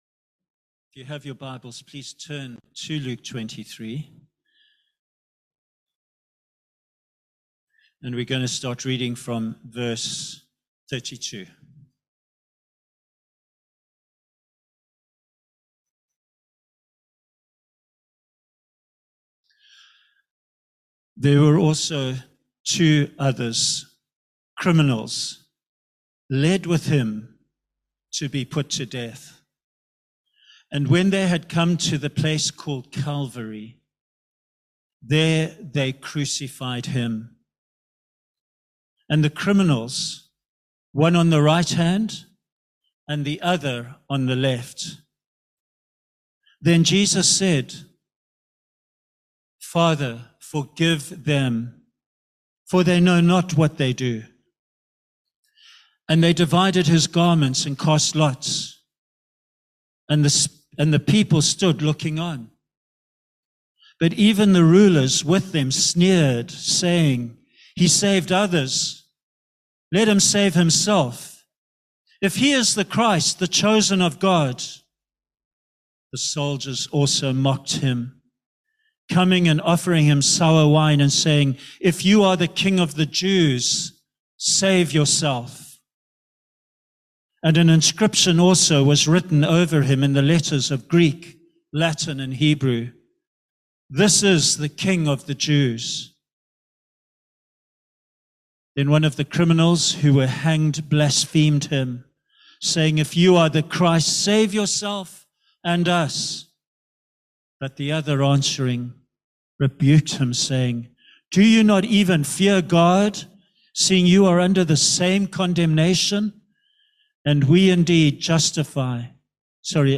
A Sunday sermon
The reading is Luke chapter 23, verses 32-47. Delivered on Good Friday (15th April) 2022.